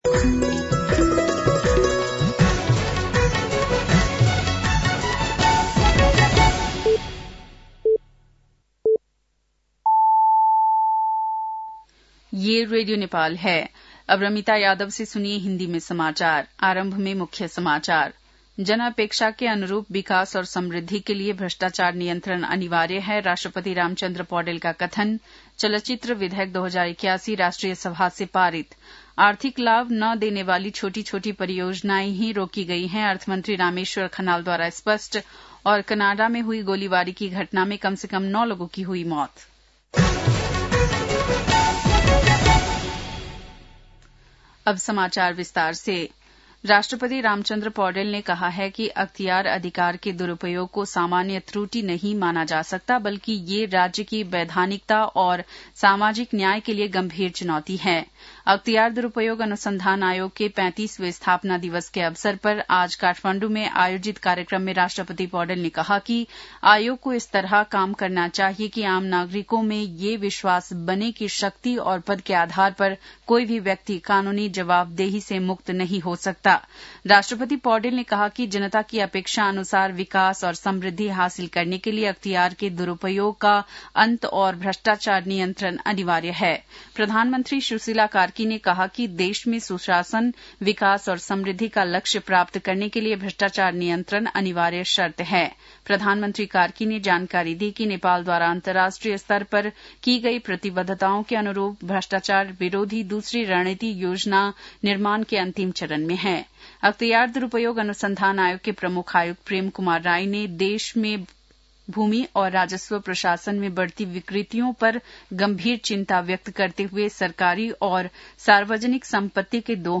बेलुकी १० बजेको हिन्दी समाचार : २८ माघ , २०८२